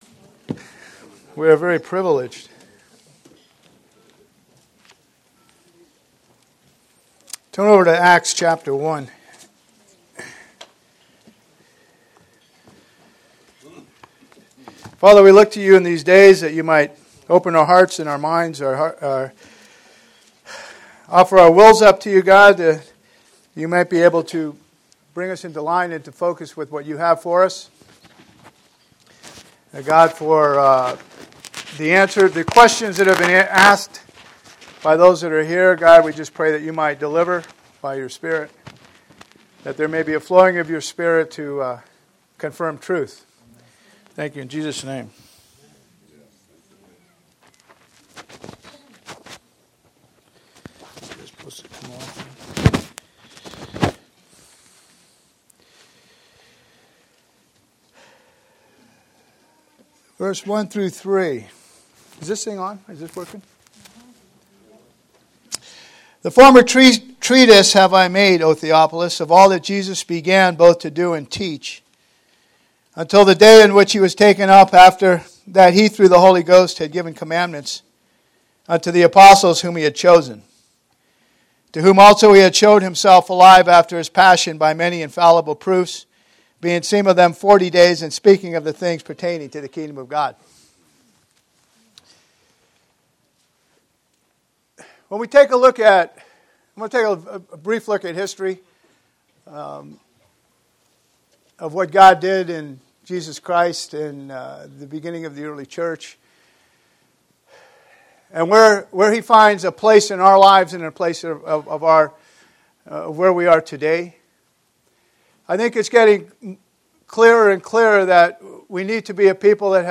Posted in 2015 Shepherds Christian Centre Convention